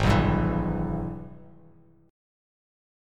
AbmM7#5 chord